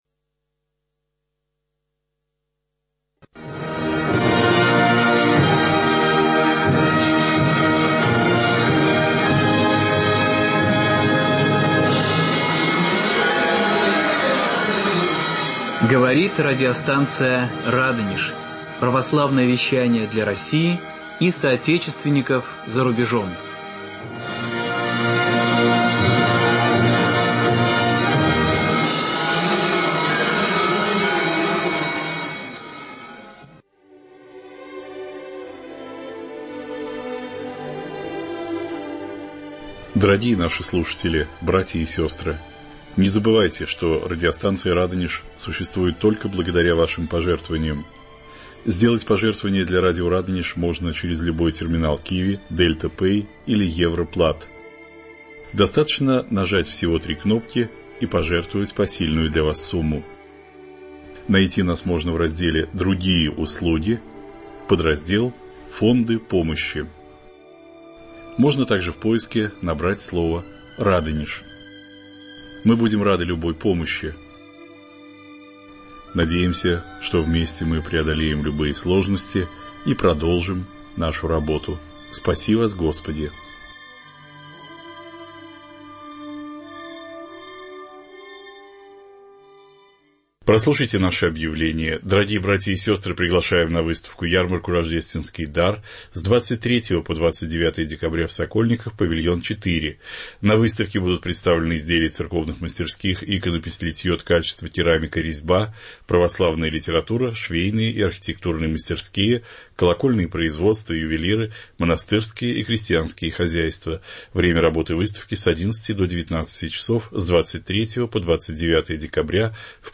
Прямой эфир. Фонд "Св. Апостола Павла"